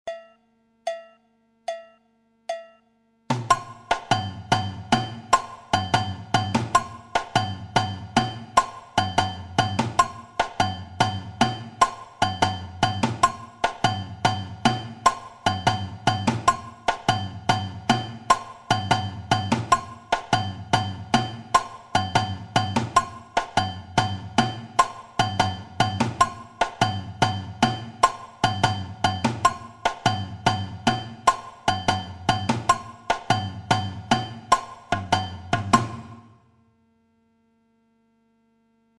Variation sur le partido alto
Le partido alto 2 Voici une variation que j'appelle partido alto 2, ou la figure des agogos colle étroitement avec le surdo.figure partido 1 à la guitare Téléchargez ou écoutez dans le player.